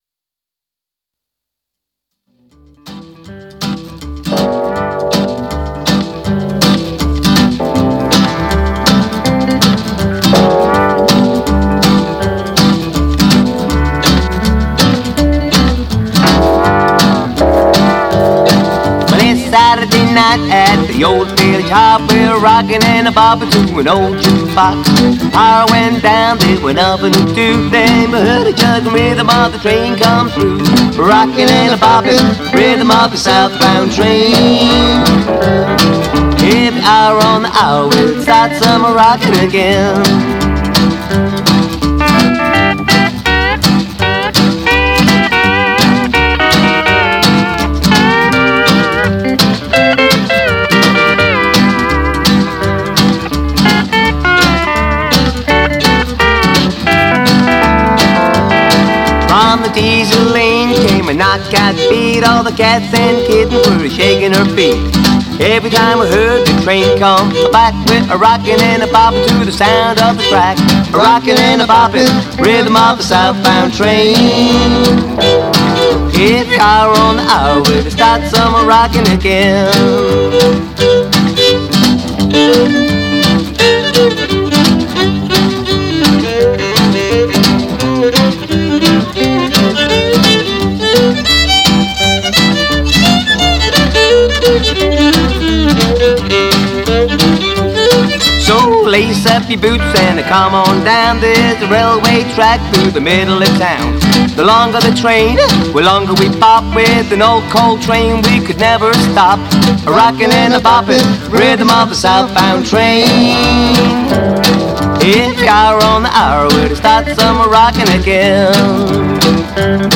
steel-guitar
violon
contrebasse
guitare